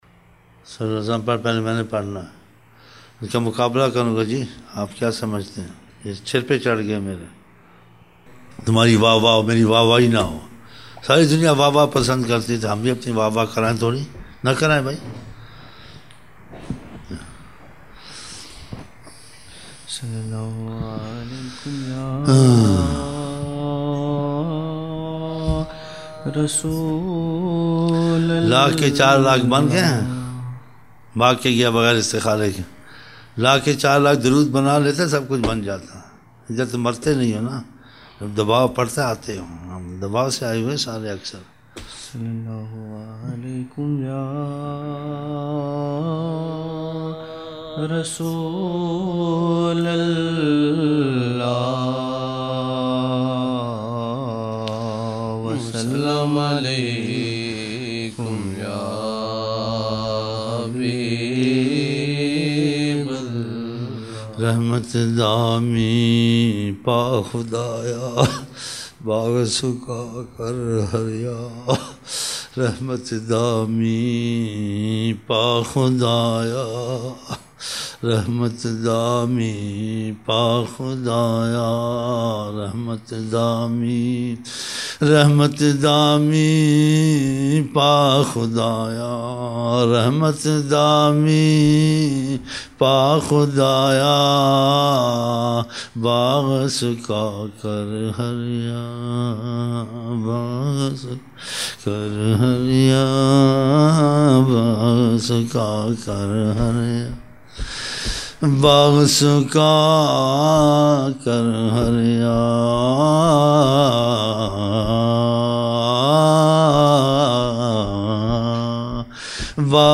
22 November 1999 - Fajar mehfil (14 Shaban 1420)
Naat shareef